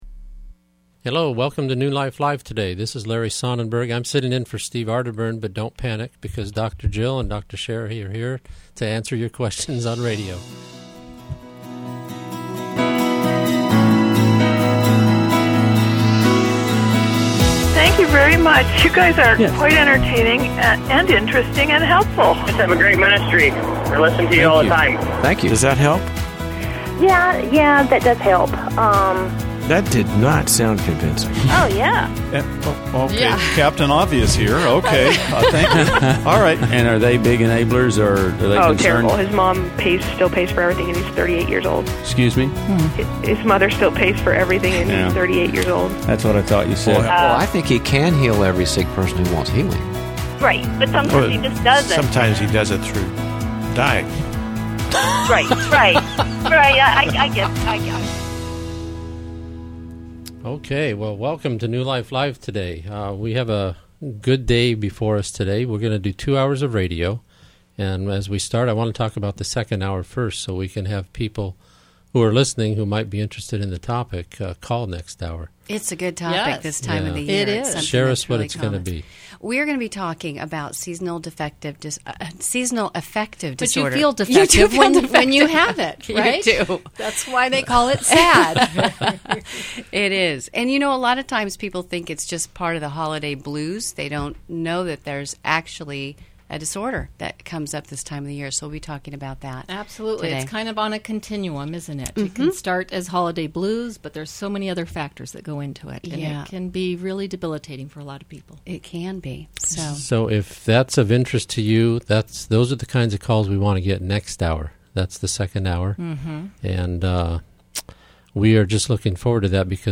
Caller Questions: I got married to help a friend get his green card.